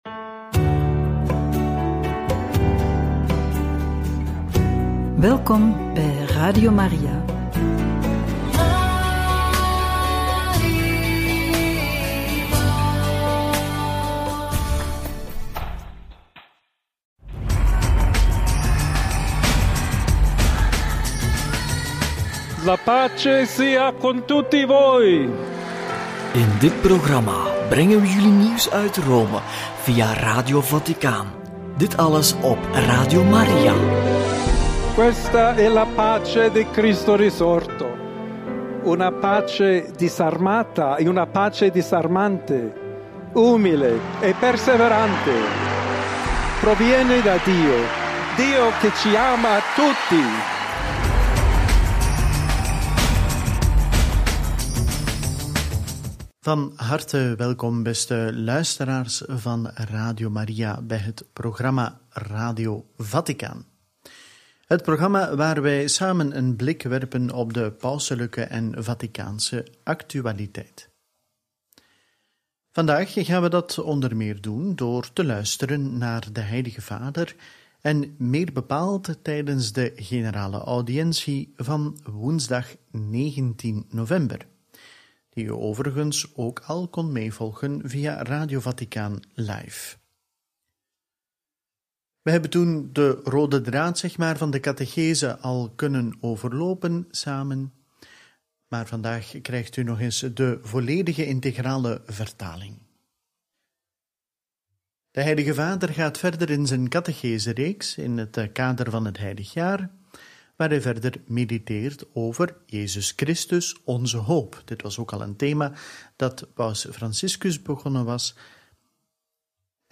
Generale audiëntie 19/11 – Paus Leo XIV bezoekt Assisi, bidt bij het graf van de heilige Franciscus en spreekt Italiaanse bisschoppen toe – Radio Maria